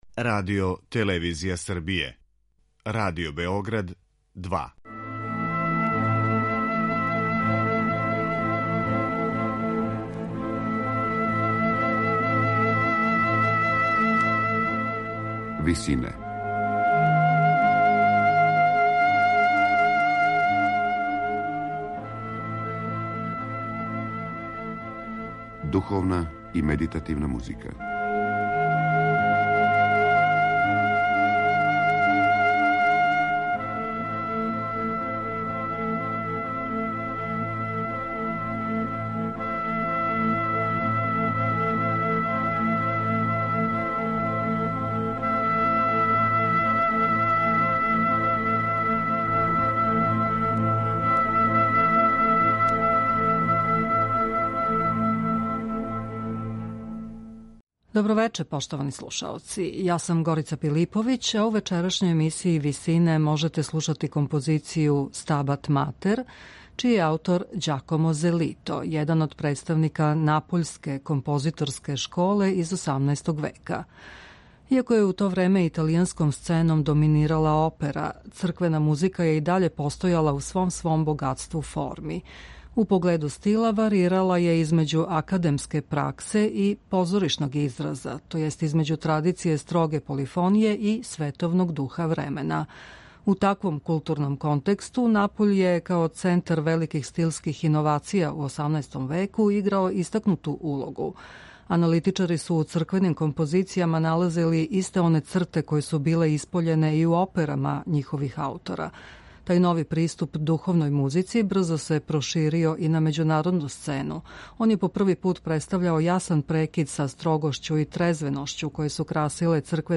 Музика италијанског касног барока